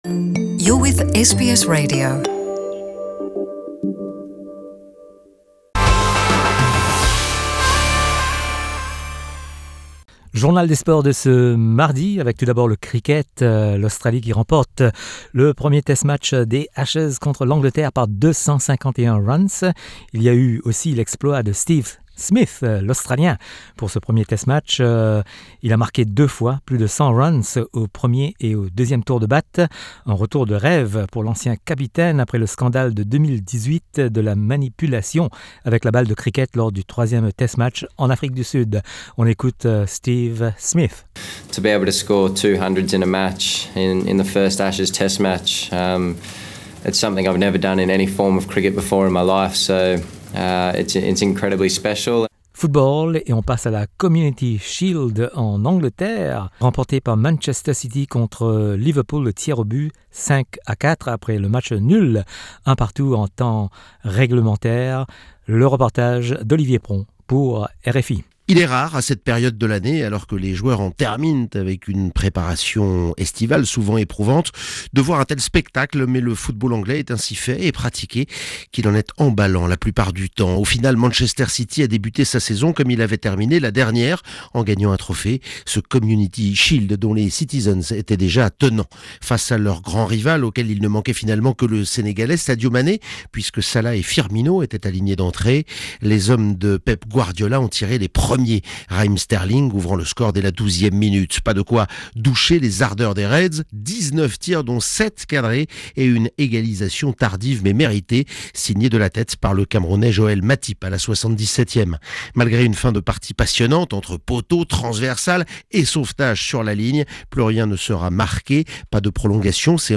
Le journal des sports du 6 août
L’actualité sportive avec les sonores de RFI.